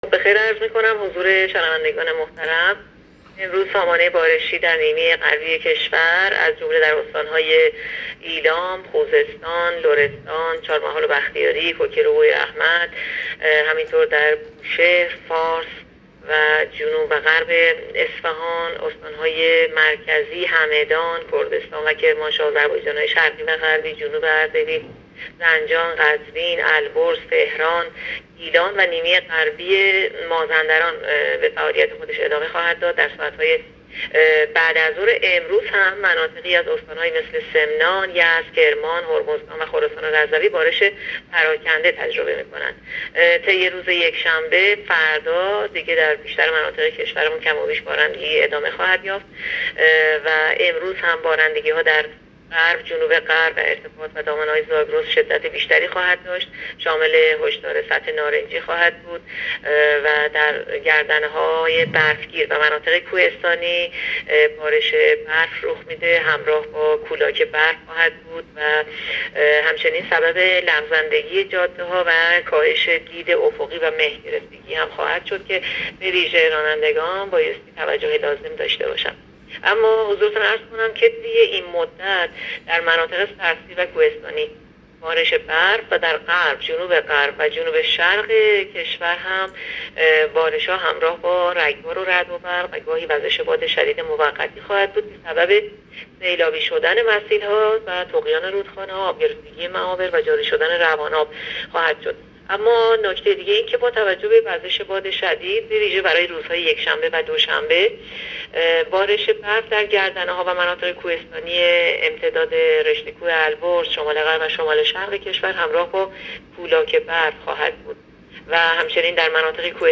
گزارش رادیو اینترنتی از آخرین وضعیت آب و هوای هجدهم بهمن؛